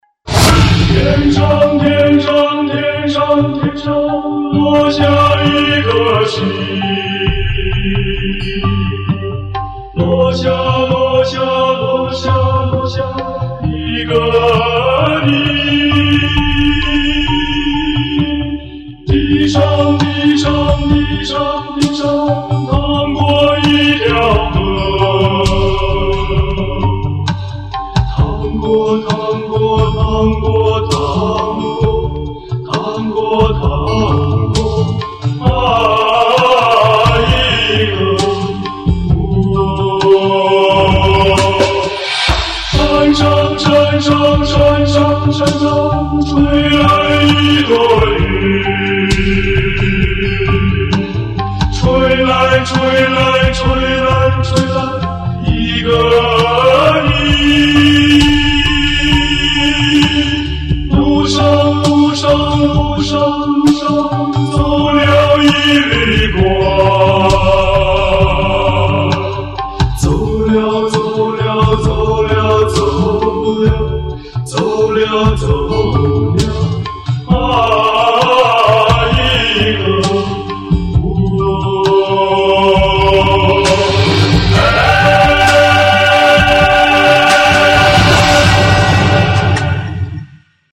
主题曲  片尾曲欣赏
深情演唱
有点沧伤。。。。。。。。